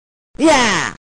fatal1gen-andywins.mp3